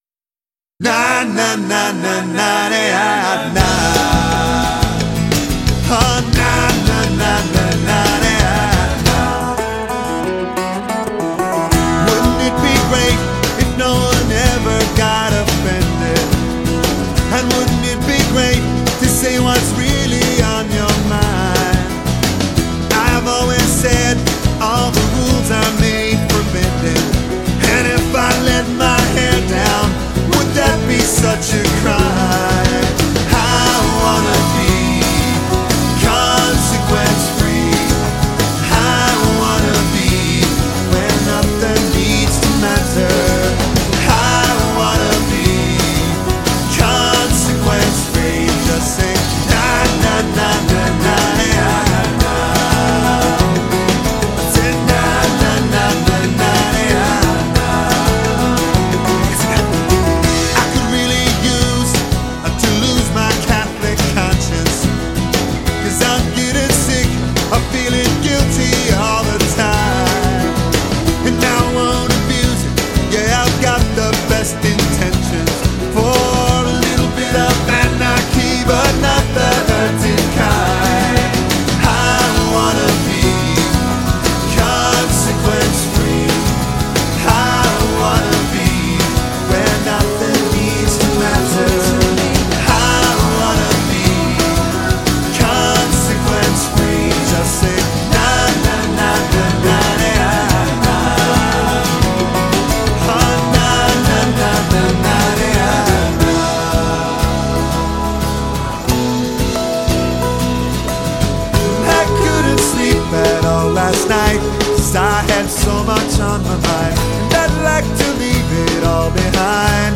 lively Celtic/Folk/Rock hybrid